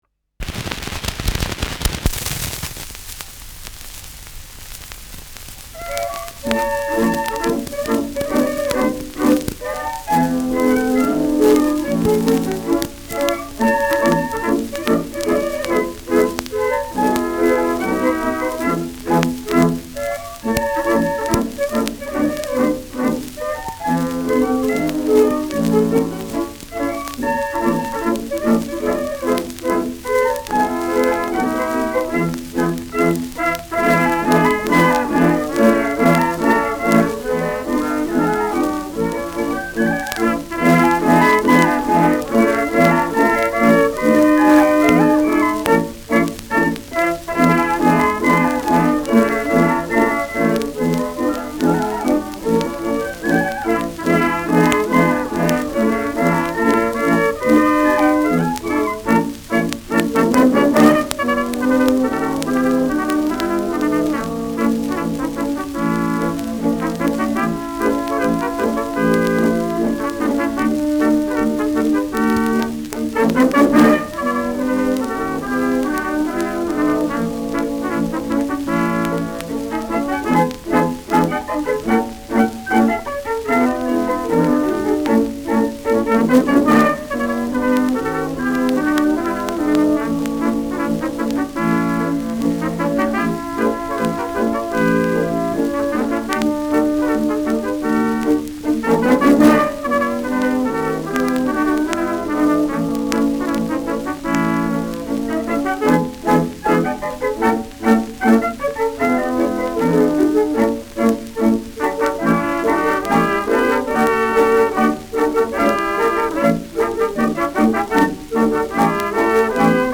Schellackplatte
[Berlin] (Aufnahmeort)